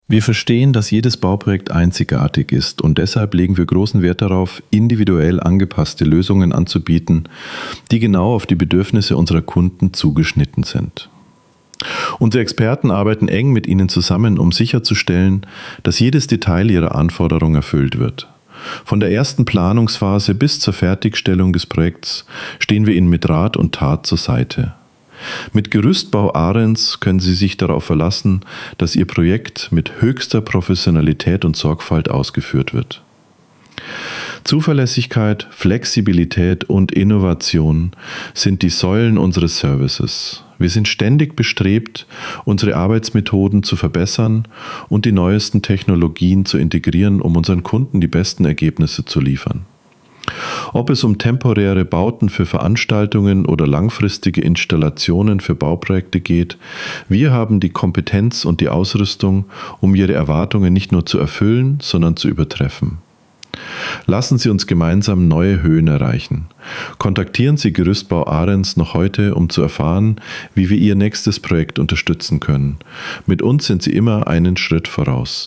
Text vorlesen – über unsere Partnerschaft im Bauprojekt